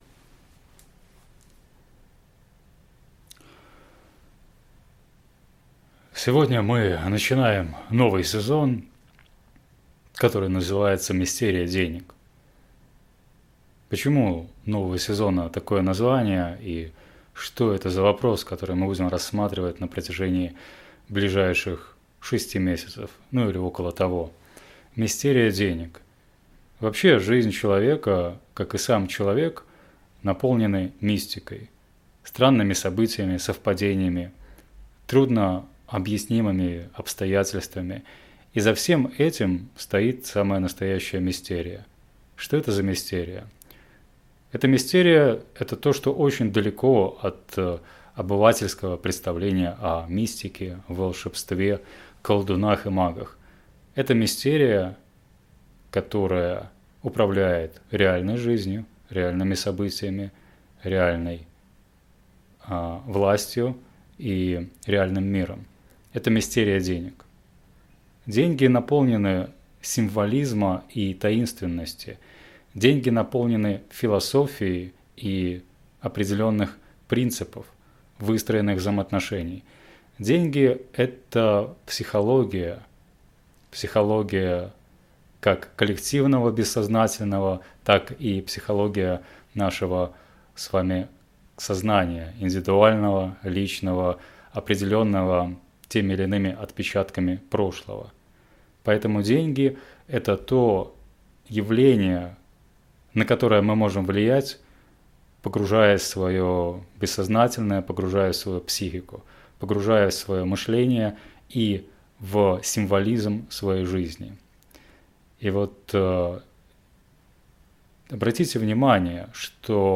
Голосовая заметка